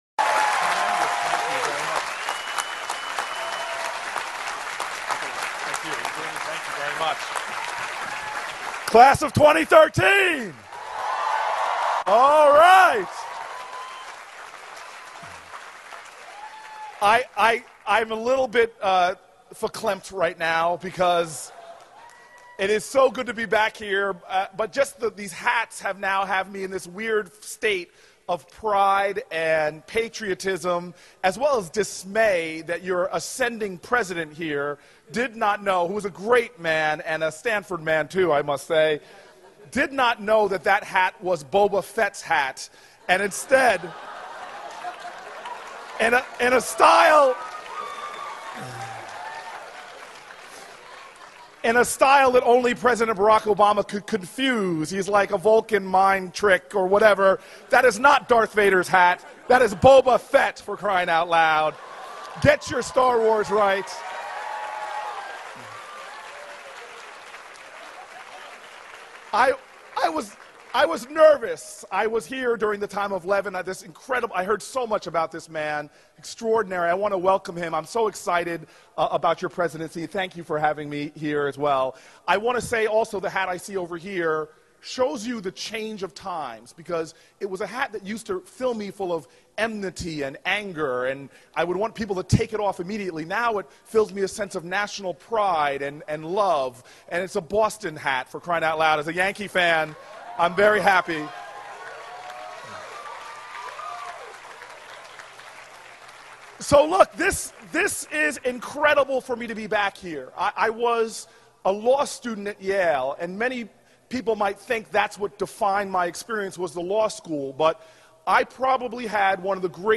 公众人物毕业演讲第433期:科里布克2013年耶鲁大学(1) 听力文件下载—在线英语听力室